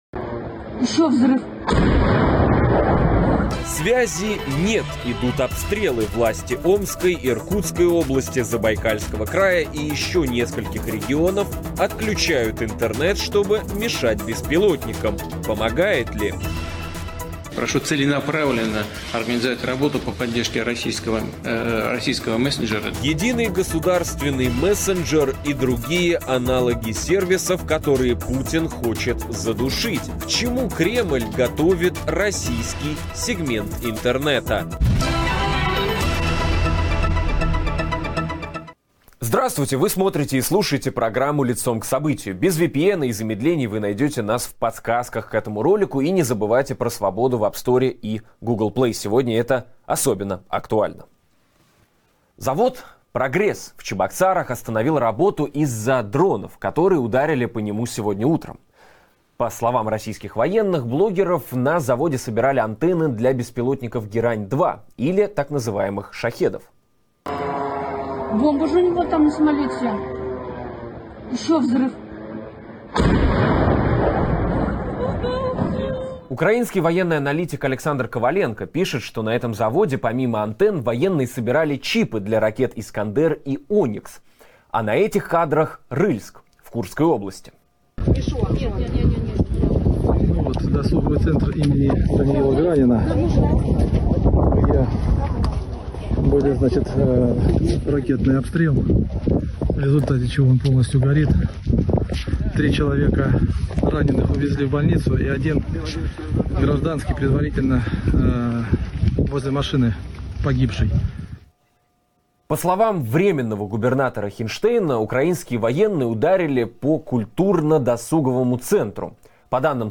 говорим с экспертом в области интернет-безопасности